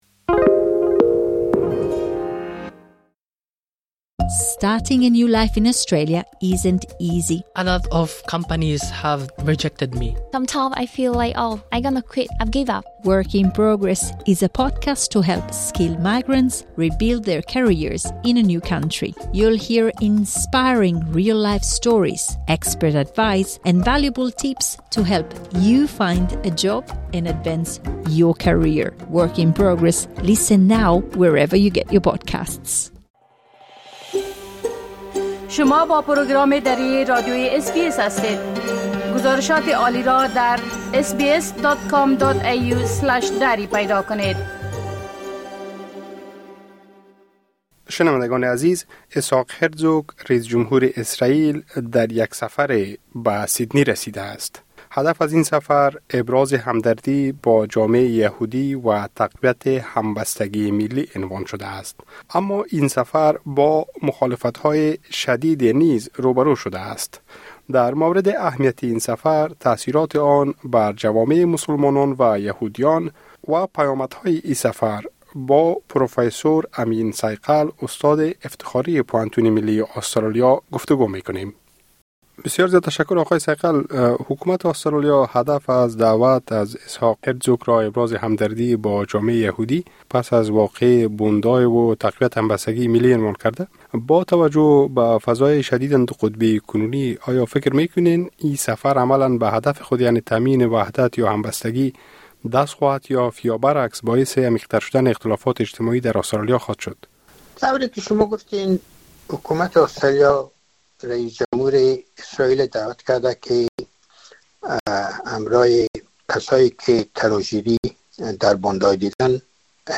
گفتگوی انجام داده‌ایم